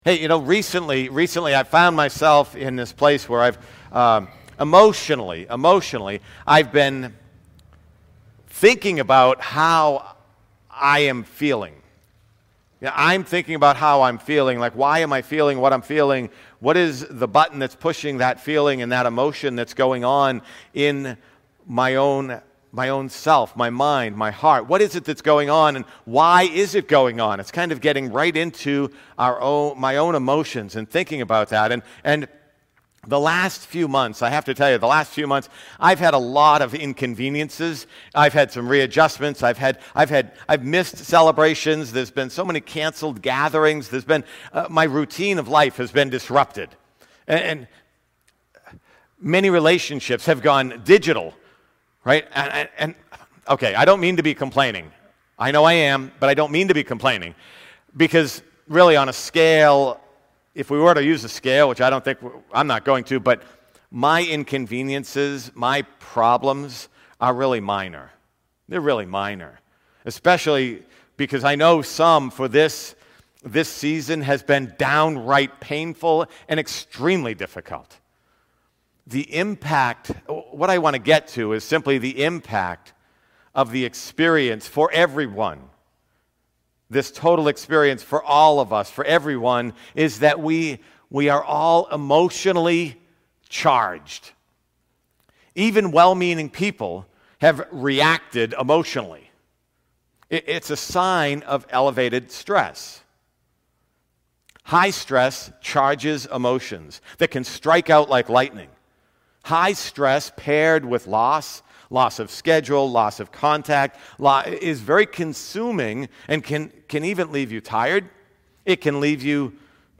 SERMONS & TEACHING